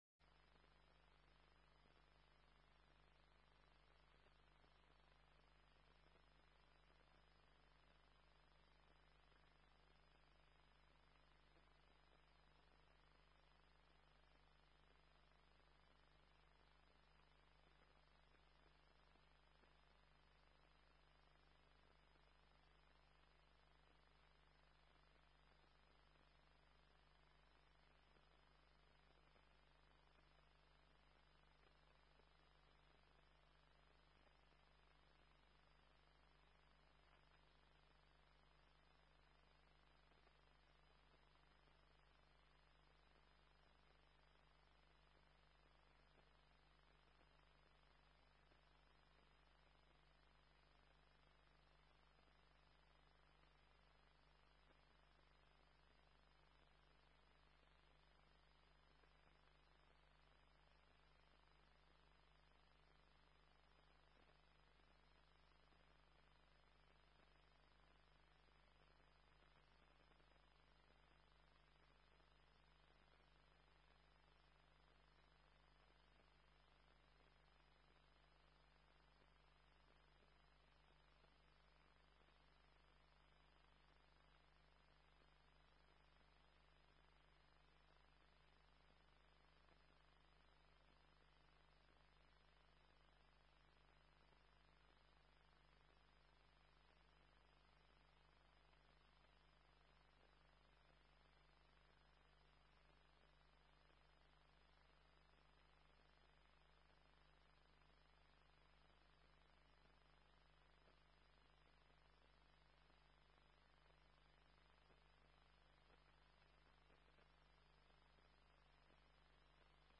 Zapis korespondencji ATC z momentu katastrofy - należy przewinąć do 03.25 UTC.